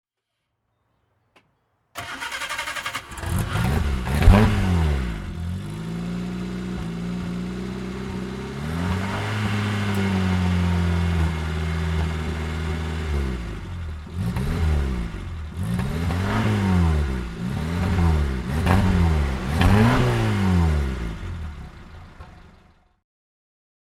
Reliant Supervan III (1971) - Starten und Leerlauf